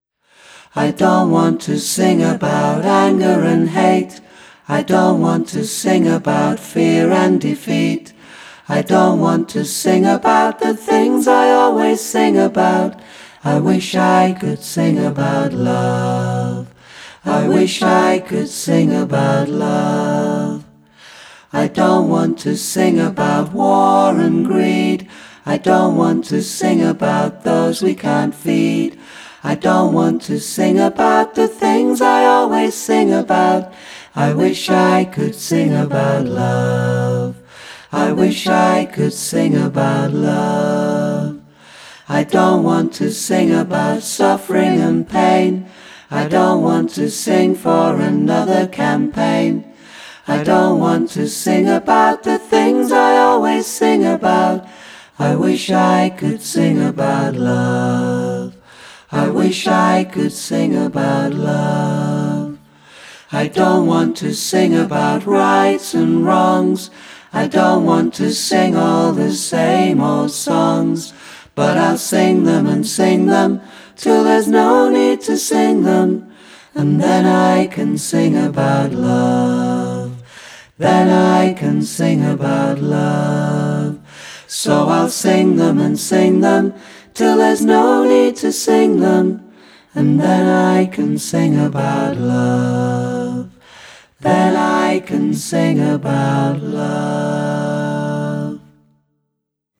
In blissful harmony.